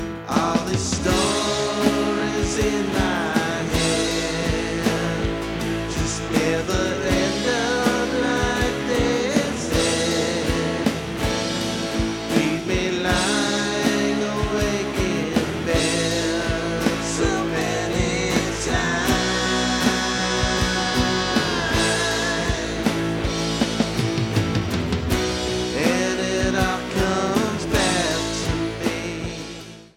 Vocals, Guitar, Bass, Drum programming
Lead Guitar